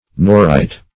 Search Result for " norite" : The Collaborative International Dictionary of English v.0.48: Norite \No"rite\, n. [F., fr. Norv[`e]ge Norway .]